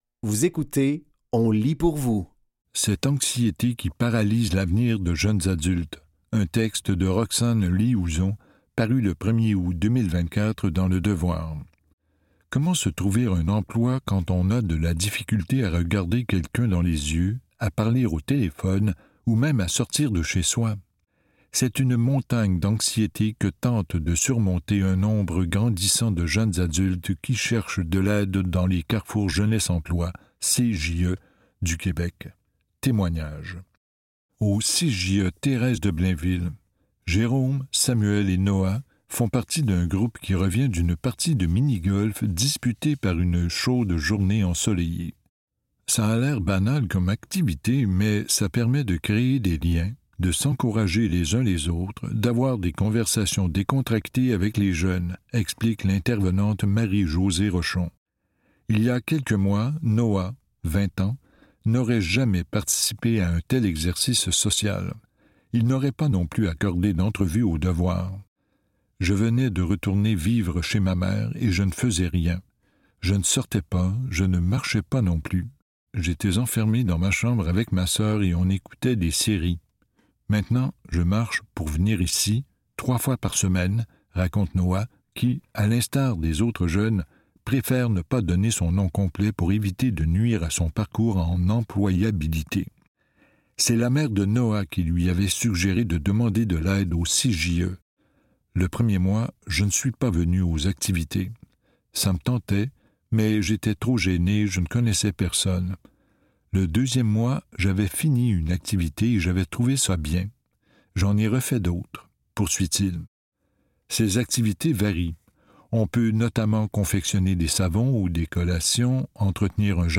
Dans cet épisode de On lit pour vous, nous vous offrons une sélection de textes tirés des médias suivants : Le Devoir, La Presse et Le Droit.